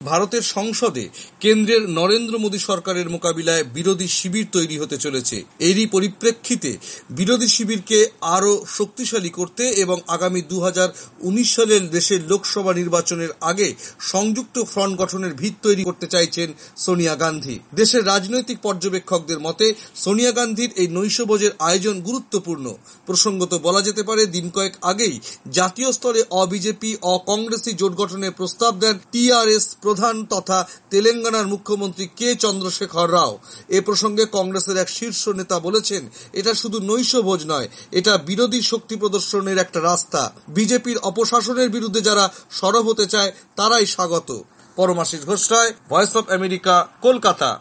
ভারতীয় জনতা পার্টি বিজেপি'র মোকাবিলায় সংযুক্ত ফ্রন্ট গড়ার নতুন উদ্যোগ নিয়েছেন কংগ্রেস নেত্রী সোনিয়া গান্ধী। আর সেই লক্ষ্যেই আগামী ১৩ মার্চ ভারতের সব বিরোধী রাজনৈতিক দলকে নৈশভোজে আমন্ত্রণ জানিয়েছেন কংগ্রেস নেত্রী। কলকাতা থেকে